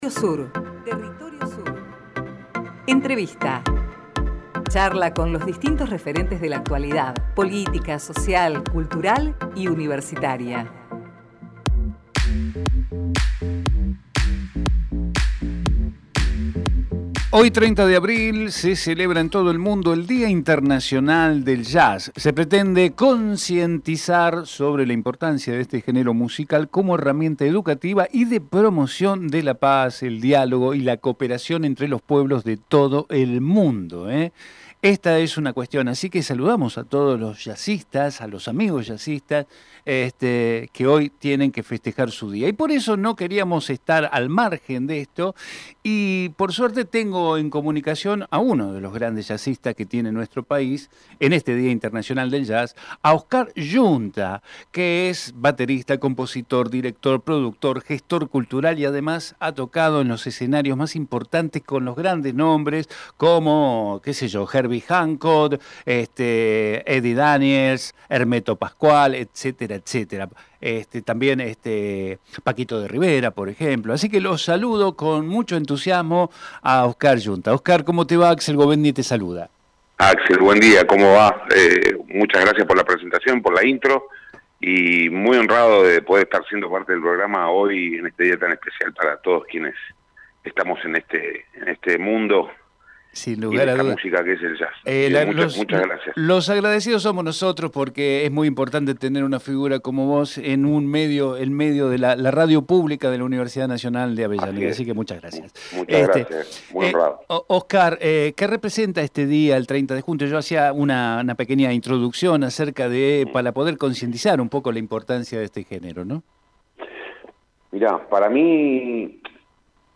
Compartimos la entrevista realizada en Territorio Sur